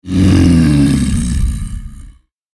クリーチャーボイス6.mp3